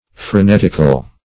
Search Result for " frenetical" : The Collaborative International Dictionary of English v.0.48: Frenetical \Fre*net"ic*al\, a. Frenetic; frantic; frenzied.